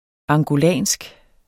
Udtale [ ɑŋgoˈlæˀnsg ]